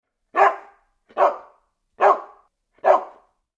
Dogs Barking Fx 4 Sound Effect Download: Instant Soundboard Button
Dog Barking Sound2,010 views